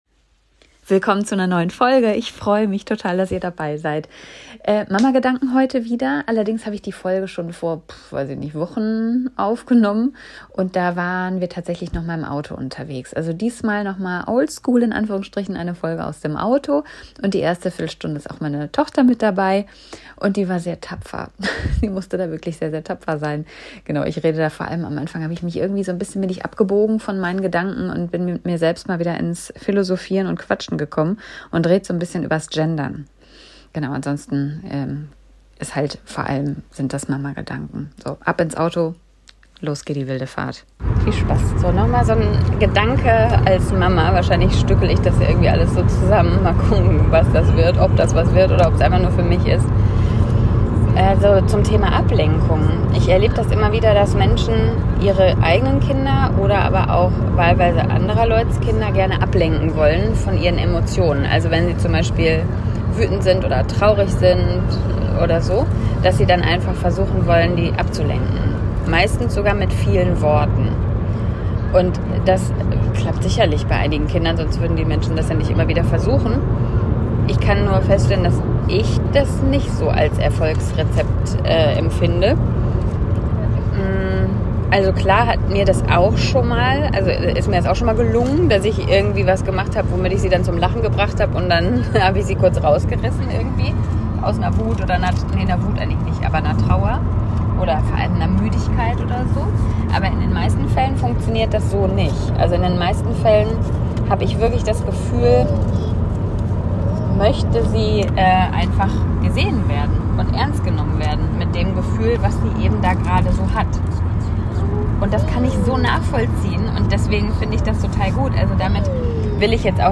Kurz zum Sound: ich hab diese Folge vor gut 2 Monaten aufgenommen - im Auto.